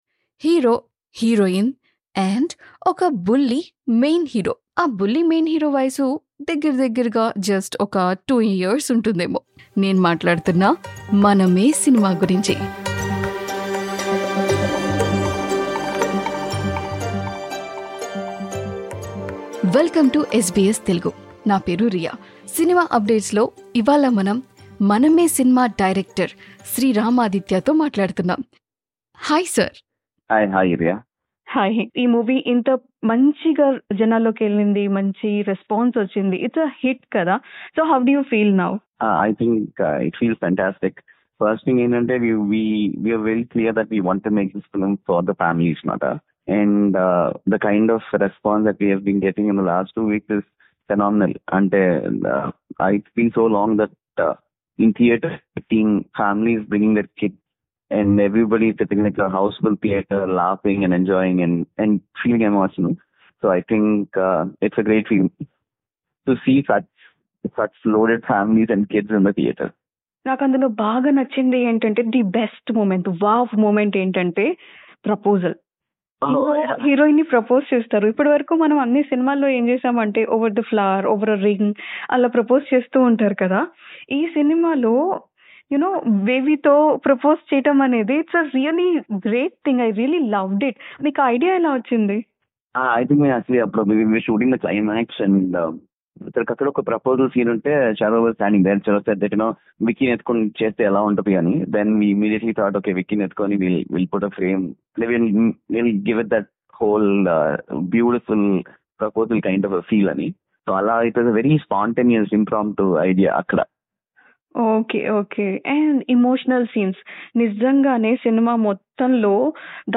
SBS తెలుగు చిట్ చాట్.